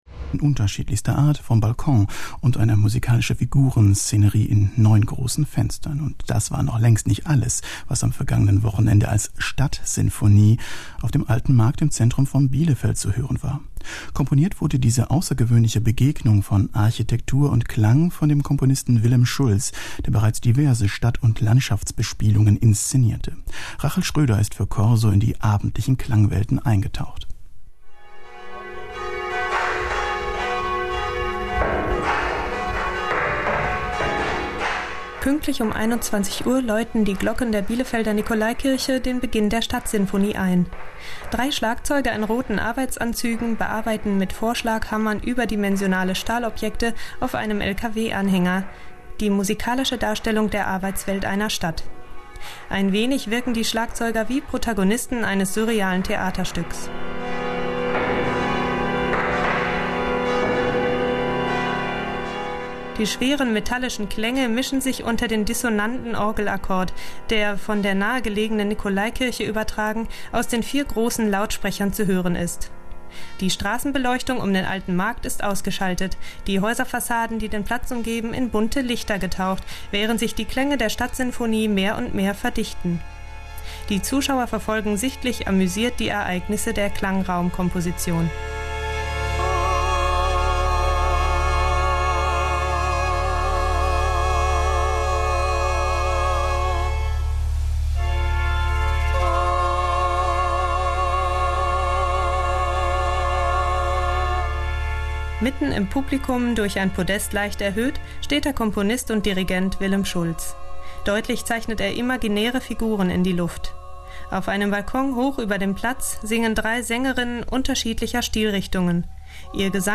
Radioberichte